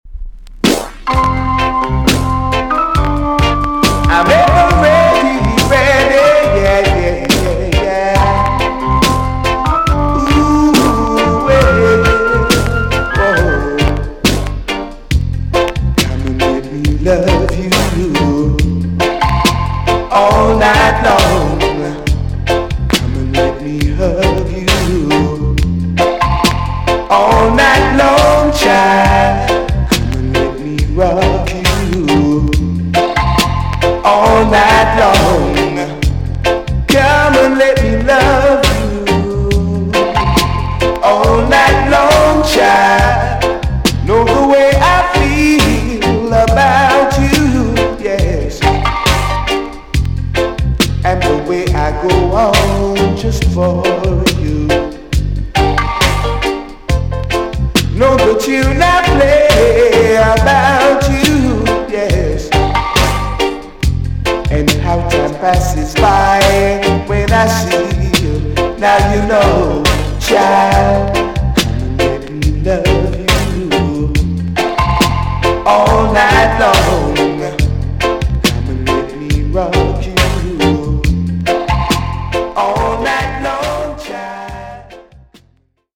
TOP >LP >VINTAGE , OLDIES , REGGAE
A.SIDE EX-~VG+ 少し軽いプチノイズがありますが良好です。